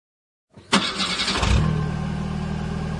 车启动.mp3